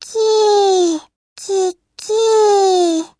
ペット精霊システム　〜鳴き声も豪華キャスト〜
サンプルボイス
各精霊の鳴き声を担当してもらっているのは実は豪華キャストの面々。